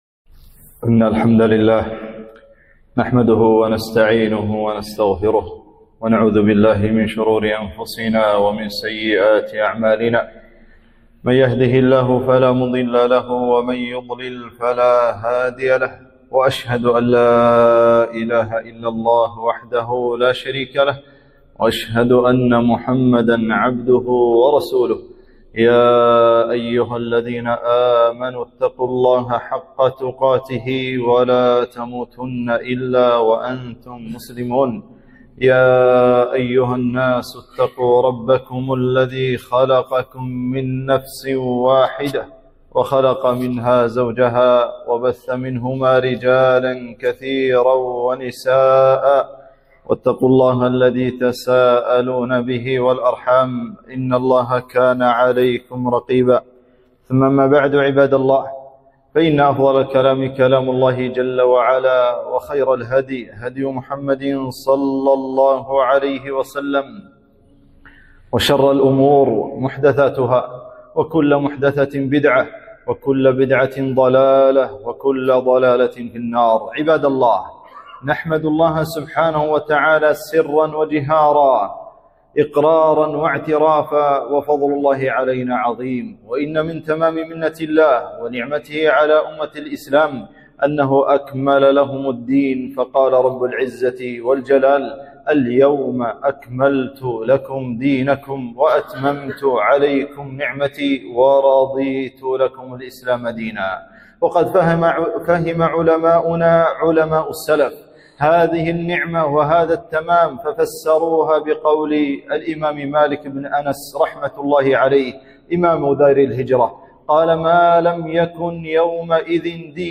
خطبة - بدعة المولد النبوي